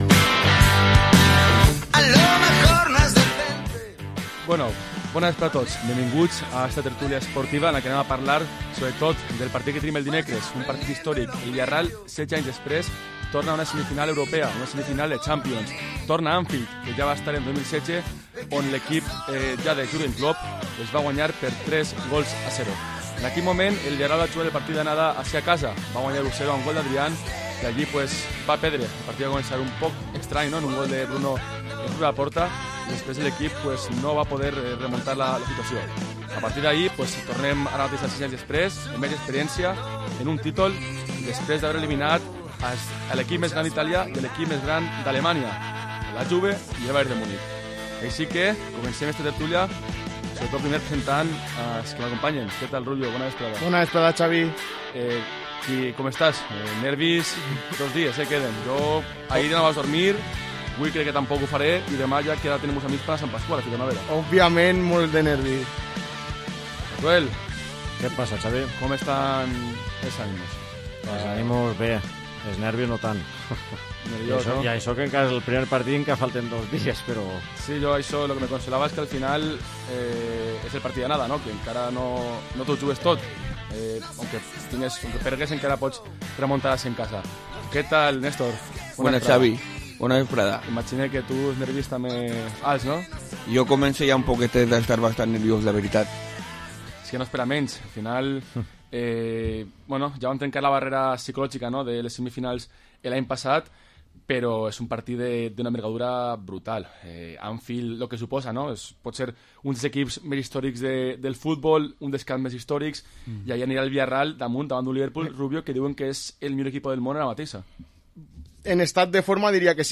Programa esports tertúlia dilluns 25 d’Abril